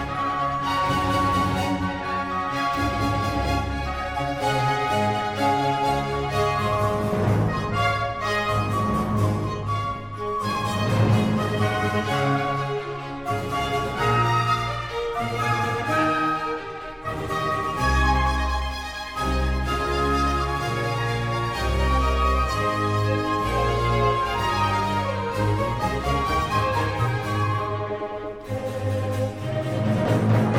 avec un goût et un esprit festifs
"enPreferredTerm" => "Musique orchestrale"
"enPreferredTerm" => "Musique vocale, profane"